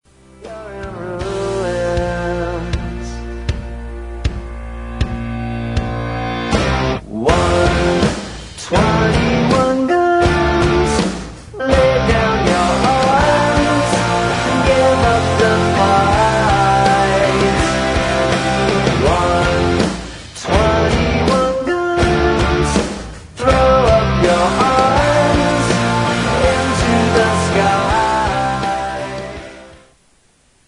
• Rock Ringtones